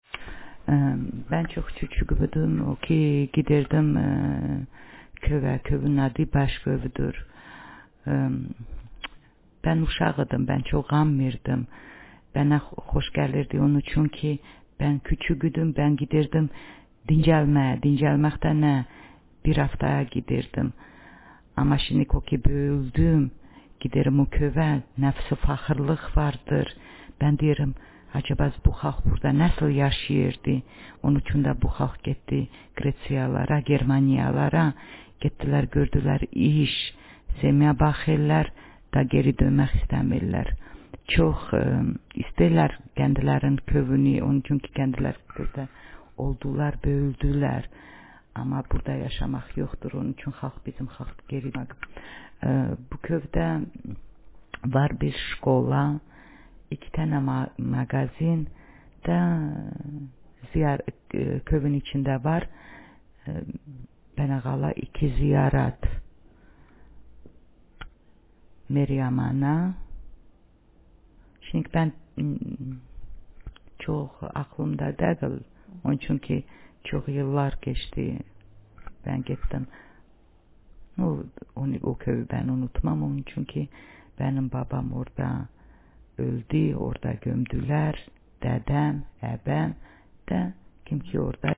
Speaker sexf
Text genrepersonal narrative